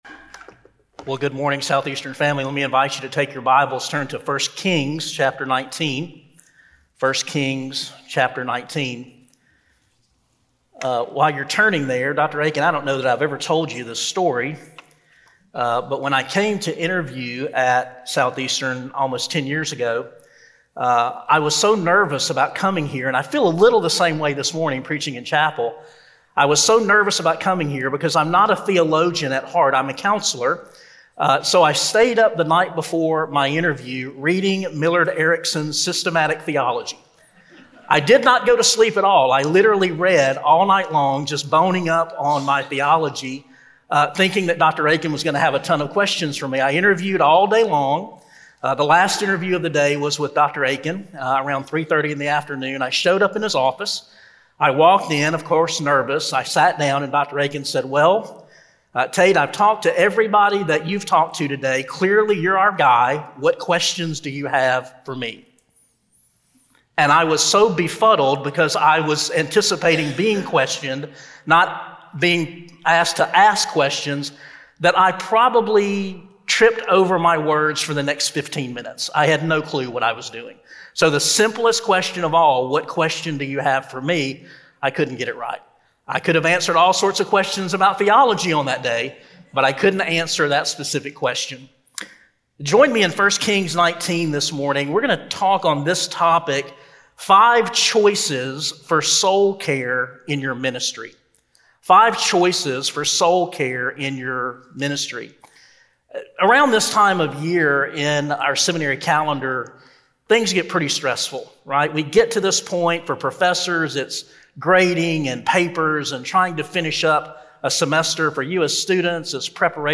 Southeastern Baptist Theological Seminary's Podcast consists of chapel and conference messages, devotionals and promotional information in both audio and video format.